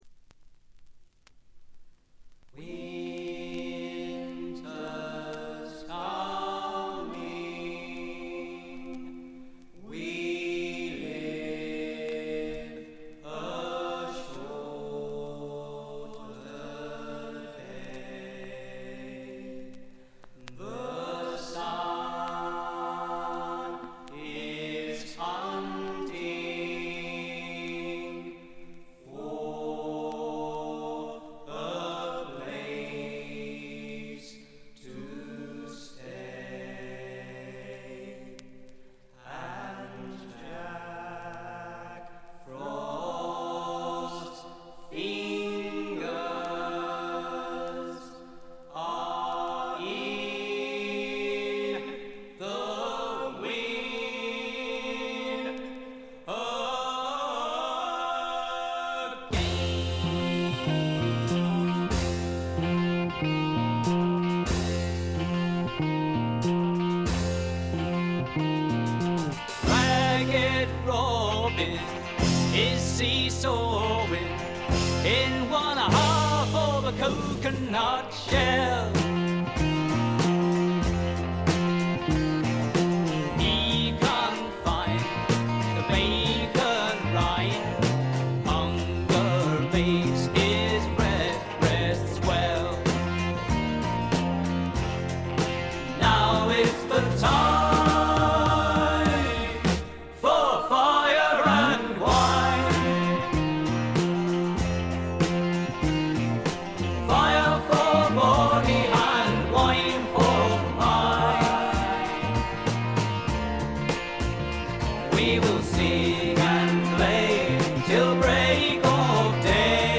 微細なチリプチ少々。
英国フォークロックの理想郷みたいな作品ですね。
試聴曲は現品からの取り込み音源です。
Backing Vocals
Drums